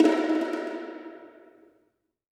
JJPercussion (132).wav